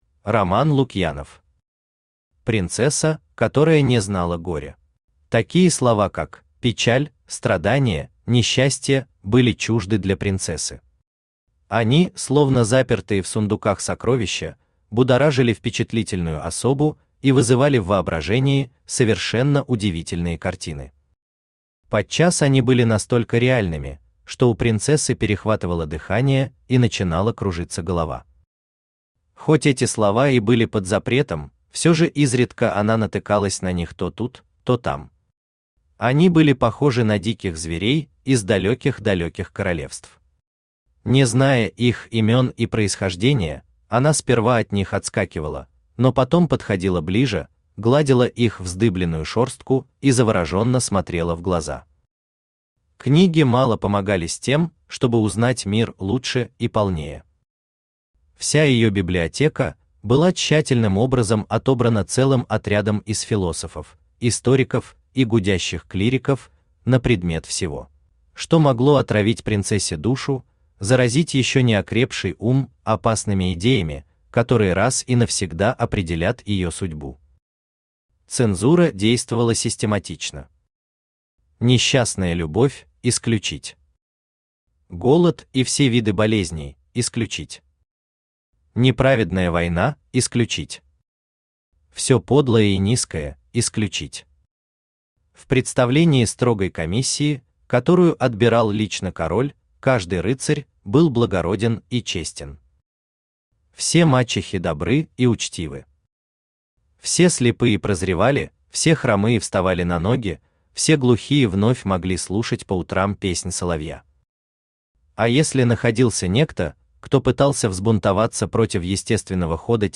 Аудиокнига Принцесса, которая не знала горя | Библиотека аудиокниг
Aудиокнига Принцесса, которая не знала горя Автор Роман Лукьянов Читает аудиокнигу Авточтец ЛитРес.